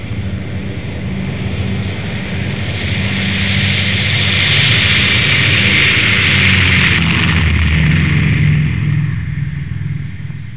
دانلود آهنگ طیاره 24 از افکت صوتی حمل و نقل
دانلود صدای طیاره 24 از ساعد نیوز با لینک مستقیم و کیفیت بالا
جلوه های صوتی